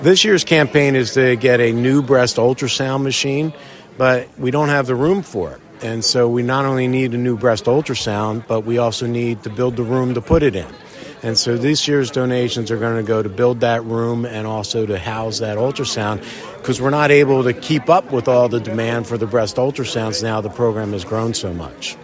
The IRMC Healthcare Foundation kicked off the 20th annual Love of Life fundraising campaign, benefitting the Women’s Imaging Center, Thursday night.